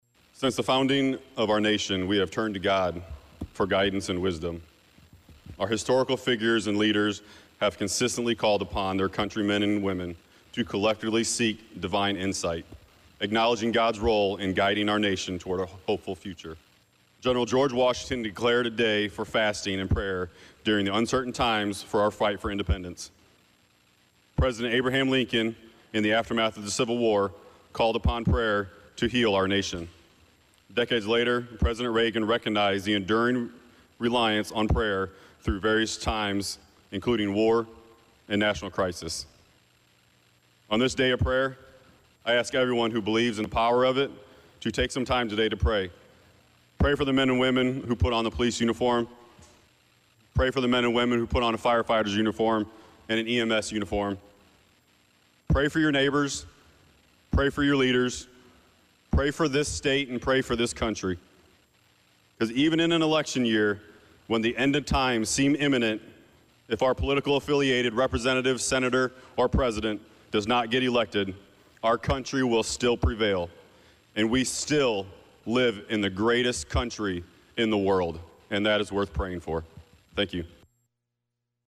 On the floor of the Illinois House of Representatives,  104th District State Representative Brandun Schweizer (R-Danville) rose to comment on the importance of the day.